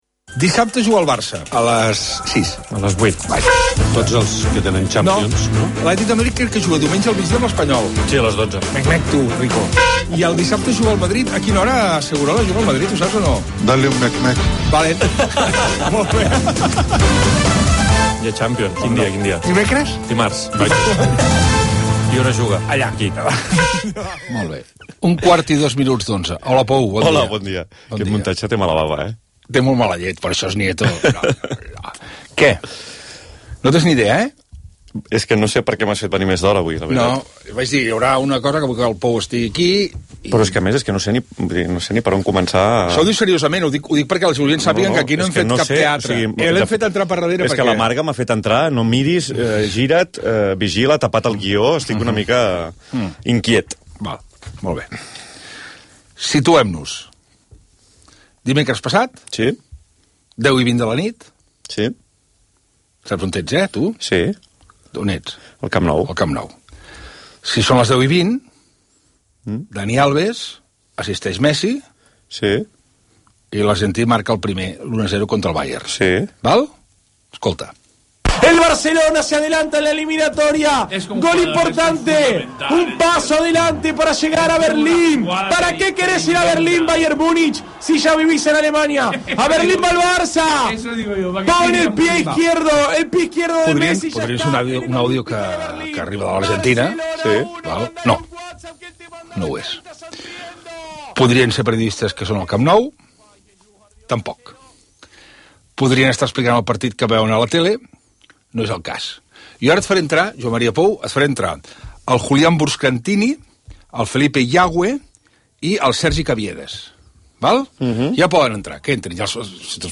Entrevista Rac1.mp3